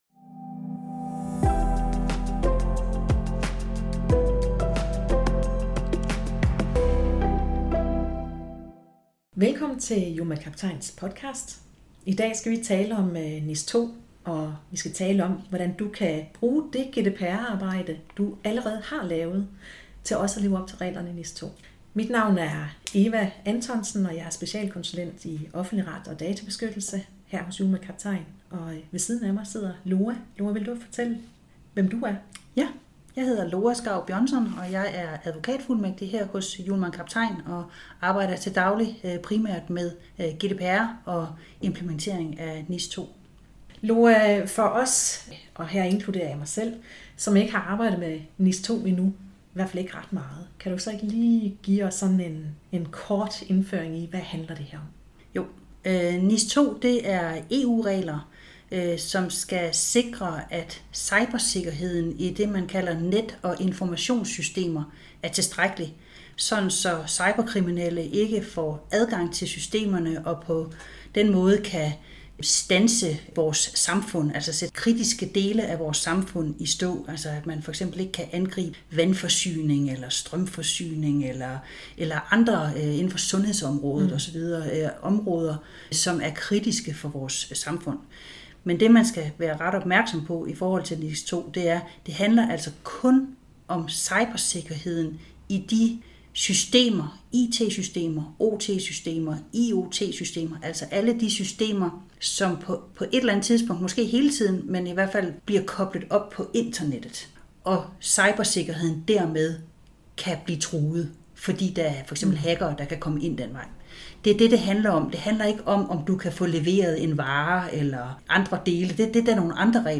Vores databeskyttelsesspecialister tager dig igennem forskelle, ligheder og overlap mellem GDPR og NIS2 i forhold til både arbejdsgange og mulige scenarier. De stiller de spørgsmål, som du måske brænder inde med.